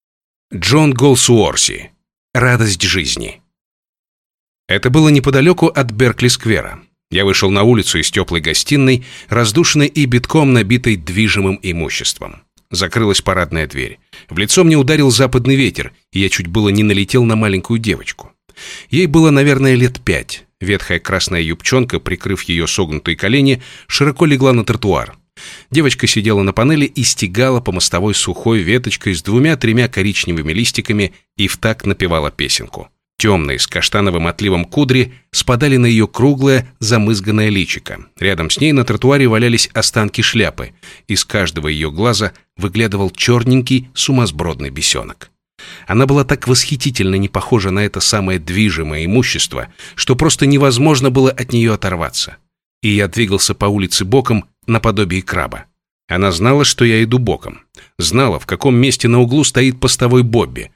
Аудиокнига Радость жизни | Библиотека аудиокниг